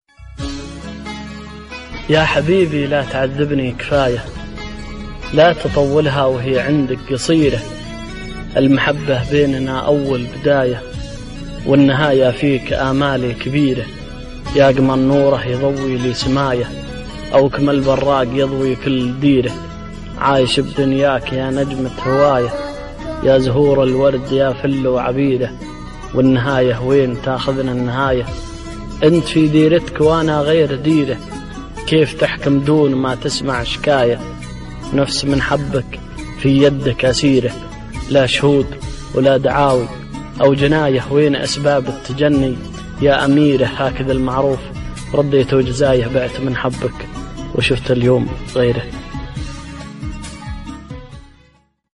غنائية